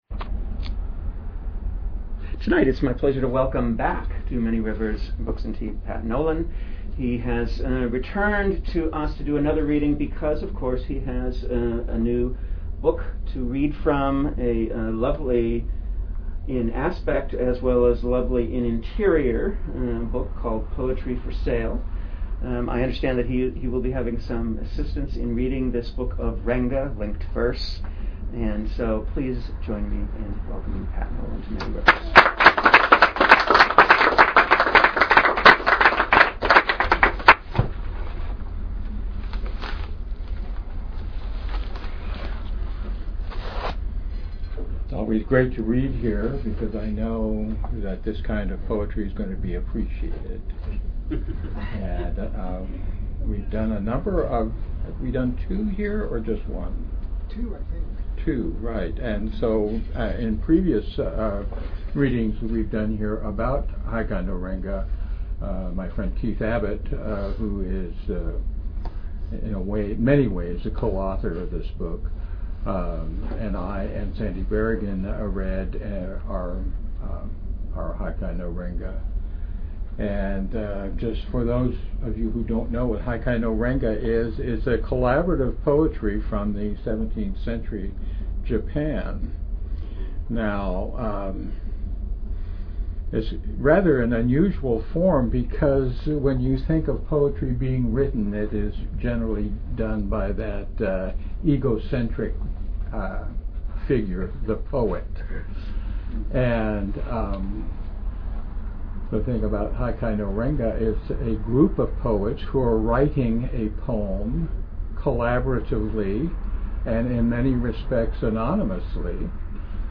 Archive of an event at Sonoma County's largest spiritual bookstore and premium loose leaf tea shop.